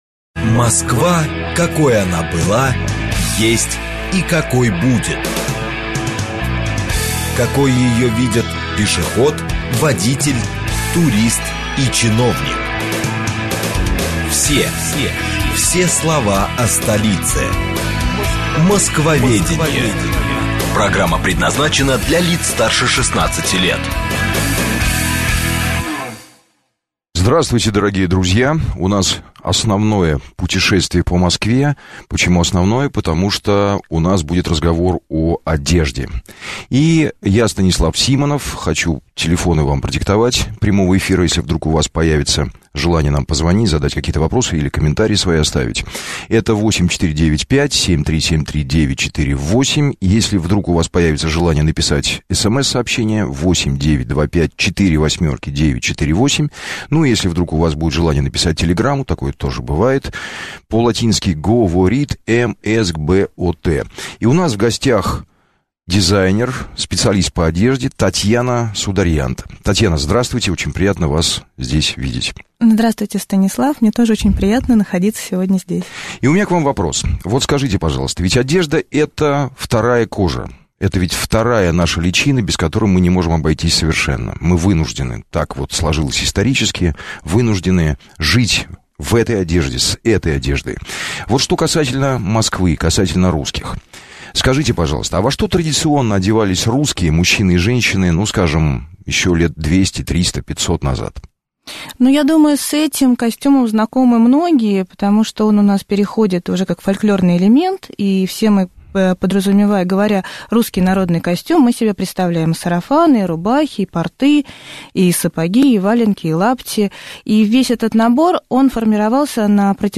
Аудиокнига Как одевались москвичи?